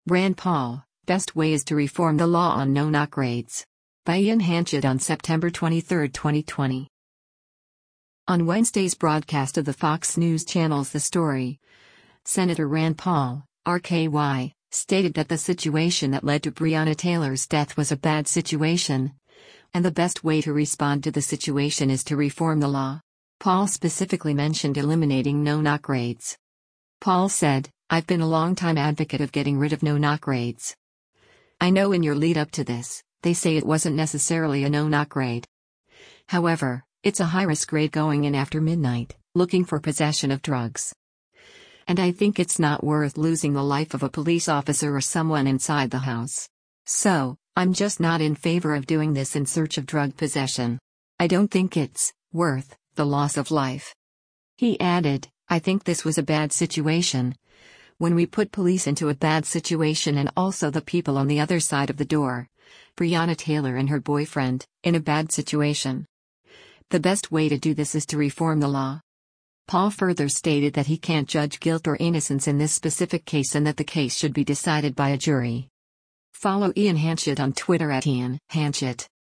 On Wednesday’s broadcast of the Fox News Channel’s “The Story,” Sen. Rand Paul (R-KY) stated that the situation that led to Breonna Taylor’s death “was a bad situation,” and “The best way” to respond to the situation “is to reform the law.” Paul specifically mentioned eliminating no-knock raids.